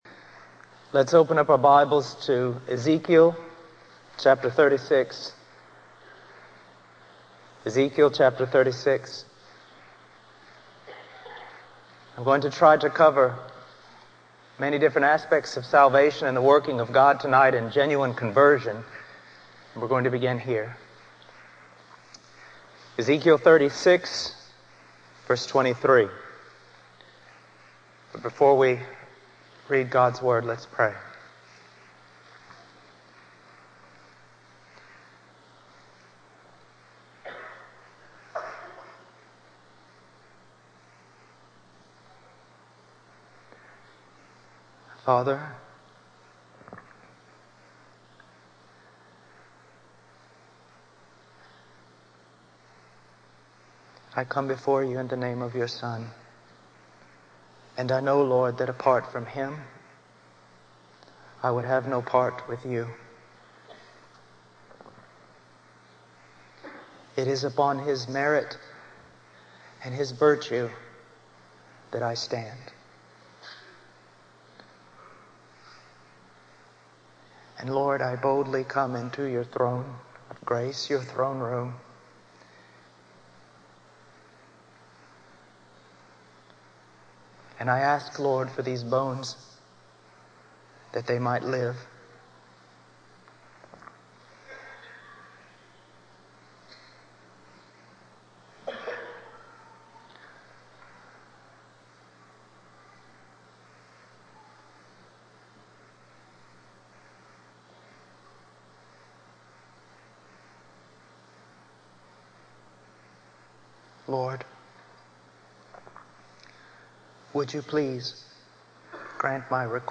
In this sermon, the preacher discusses the importance of preaching the word of God in a way that truly reflects the power and glory of Yahweh. He criticizes the current state of preaching, which often reduces the gospel to a simple formula and fails to address the sinfulness of man and the holiness of God.